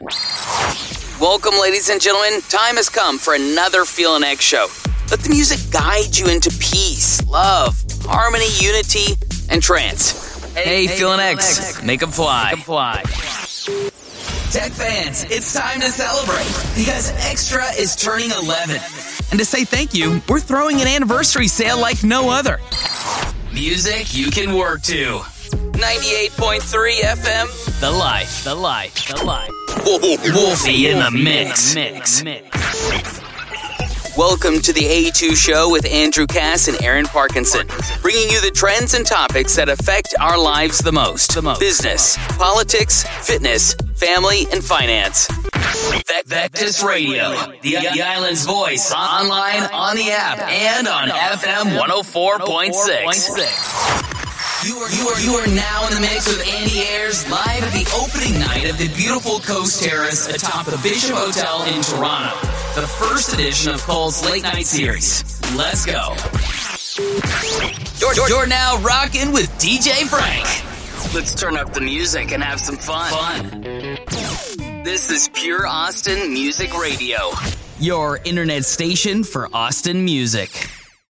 Male
Radio Imaging
His natural voice is clean, clear, and youthful. His delivery is conversational and upbeat with a humorous lilt and natural likeability.
I have dialed in my studio to provide professional broadcast quality ready for use.
Mic: Sennheiser MKH416 and UA Sphere LX